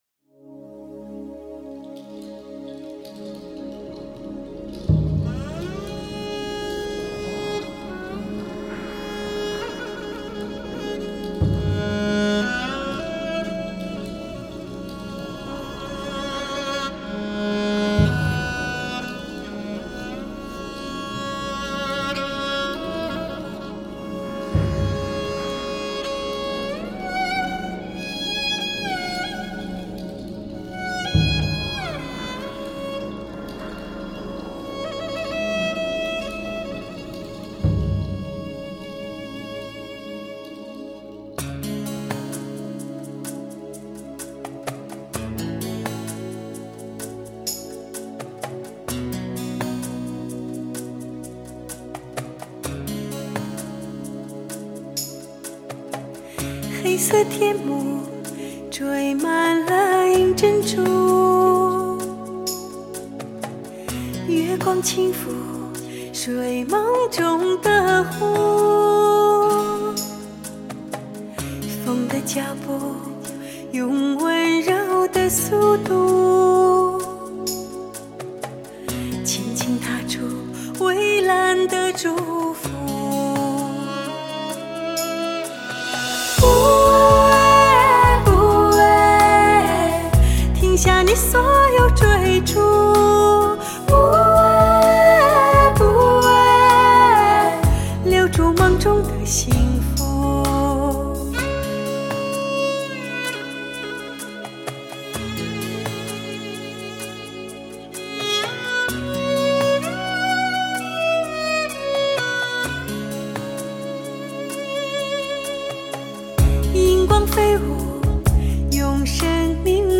在配乐中，无论是轻爵士元素还是流行风，无论是清淡的钢琴曲还是悠扬的马头琴，音乐制作团队都精心编配，
大家都力图让这沁人心脾的好音乐，柔美恬静的声音，给快节奏生活下的人们带来一种安逸舒适的感觉。